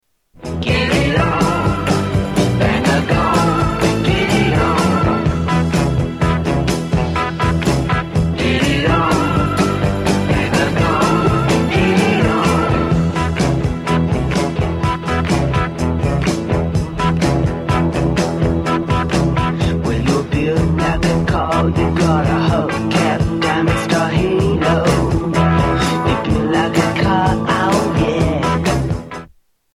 Tags: Sound Effects Rock Truetone Ringtones Music Rock Songs